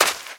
STEPS Sand, Run 18.wav